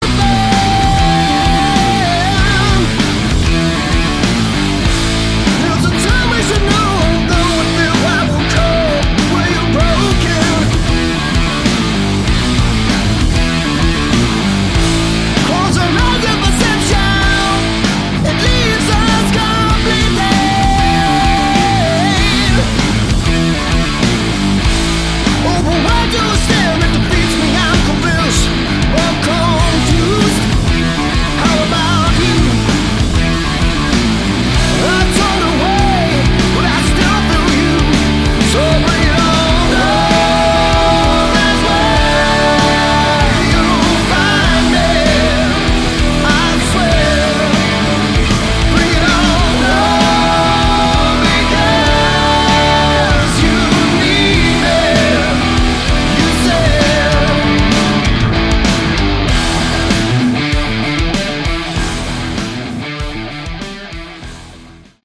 Category: Hard Rock
guitar
bass
vocals
drums